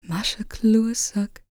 L  MOURN C01.wav